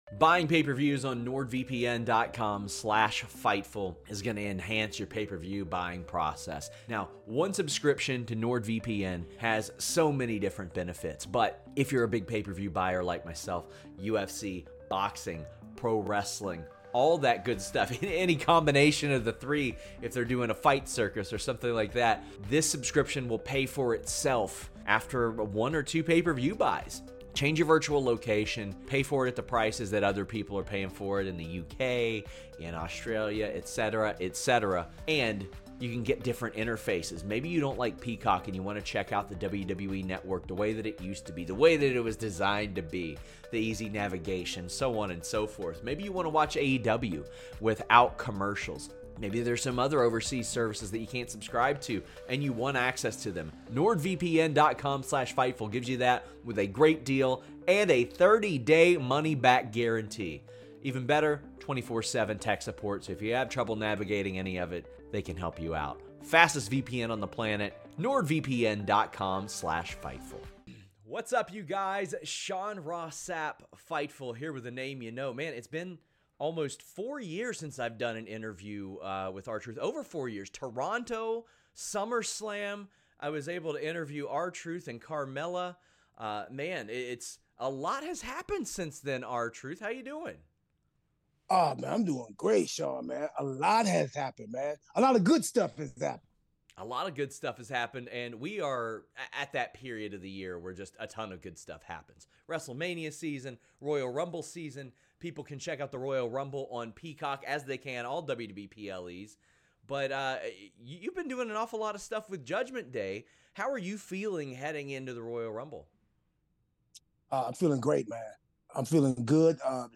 R-Truth Loves Judgment Day, Talks JD McDonagh's Big Head, Royal Rumble, PACMAN Jones | Interview | Fightful News